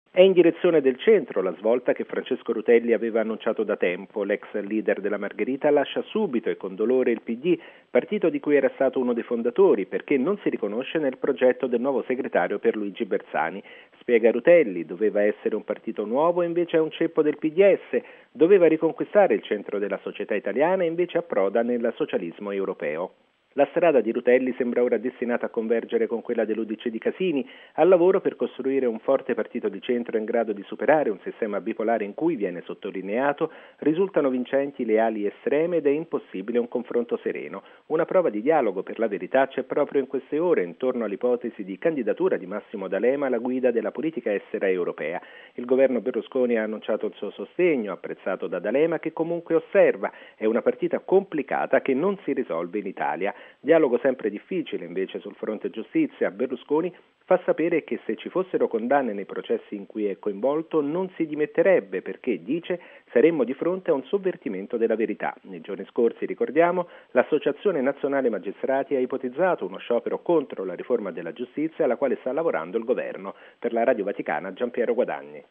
E’ quanto fa sapere il premier Berlusconi a proposito dei processi che lo riguardano. Intanto nel Pd si fanno i conti con l’addio ufficializzato oggi da Francesco Rutelli. Servizio